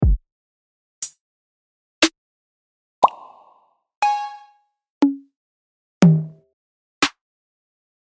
drumsprite.mp3